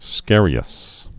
(skârē-əs) also scar·i·ose (-ōs)